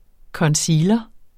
Udtale [ kʌnˈsiːlʌ ]